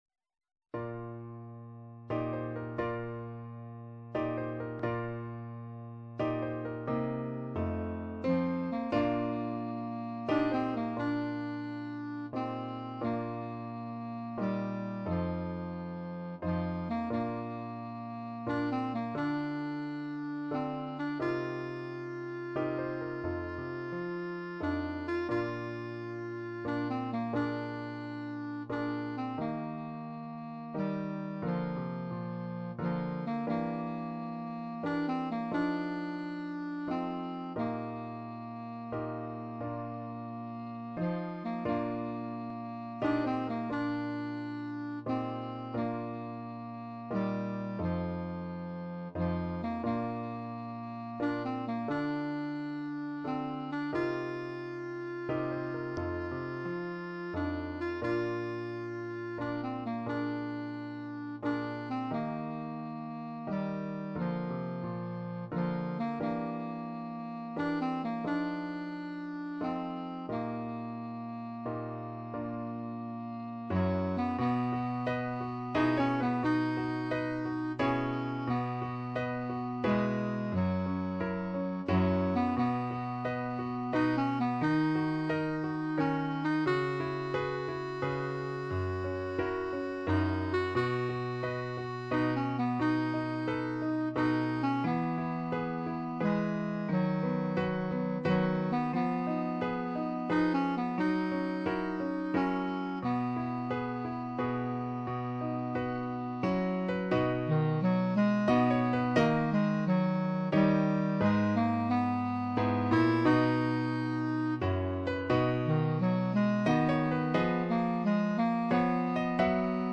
Besetzung: Instrumentalnoten für Klarinette